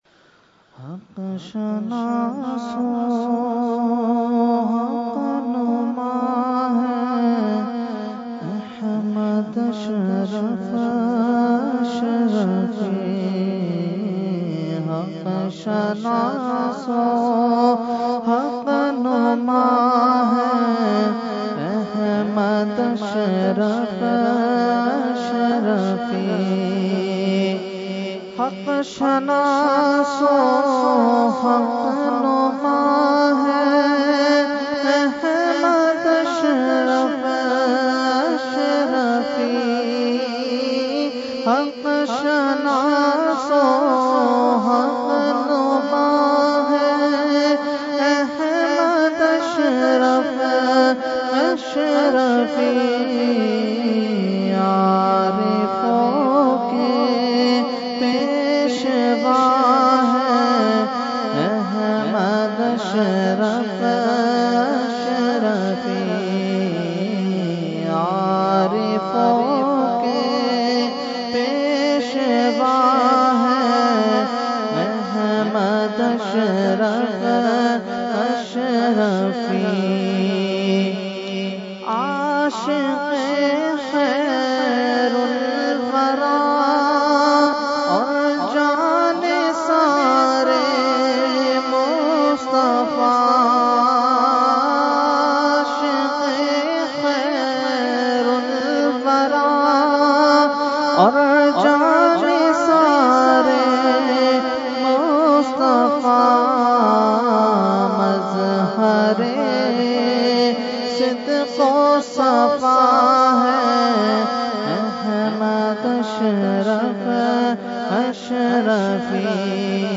Category : Manqabat | Language : UrduEvent : Urs Qutbe Rabbani 2018